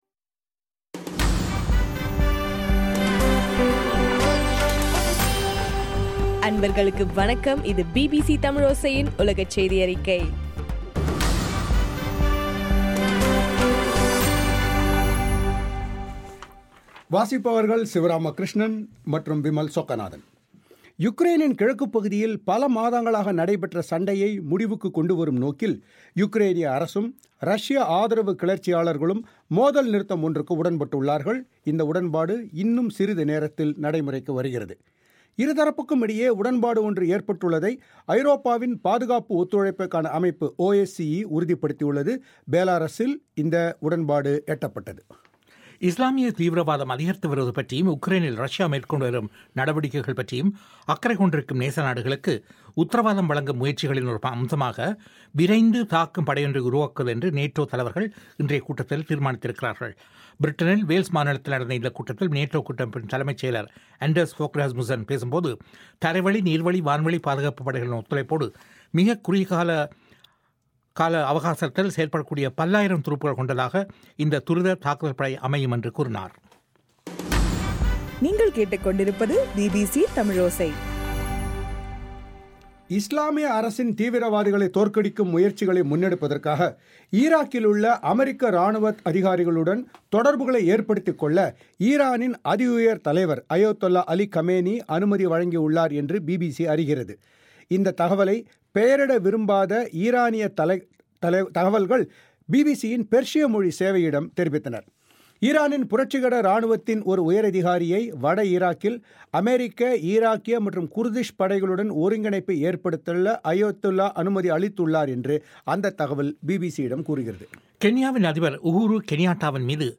தமிழோசை செய்தியறிக்கை செப்டம்பர் 5